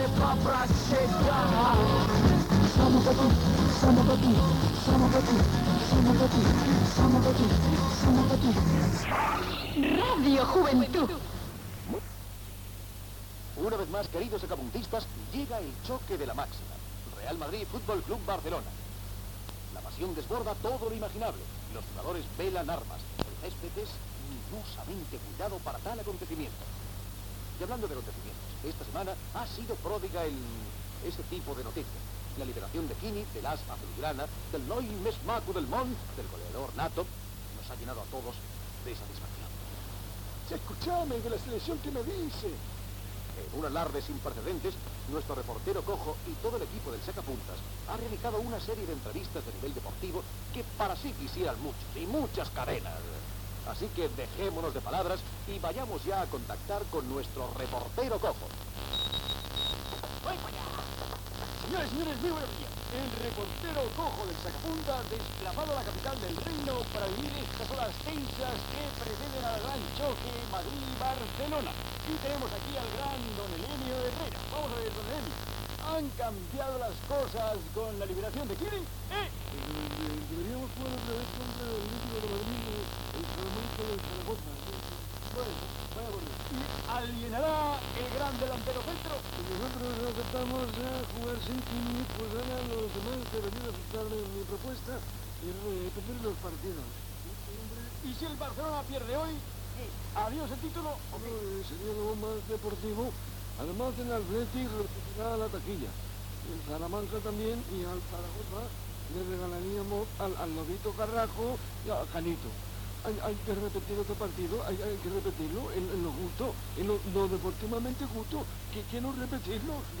Tema musical, indicatiu de la ràdio, actualitat esportiva
unitat mòbil des del Palacio de la Monloca
sintonia de sortida i indicatiu de l'emissora Gènere radiofònic Entreteniment